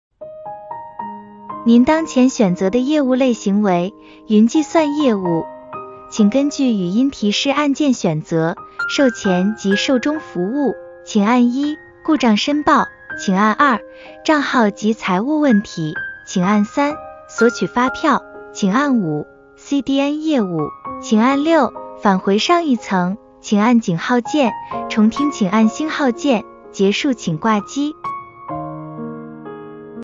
IVR音频录制试听案例：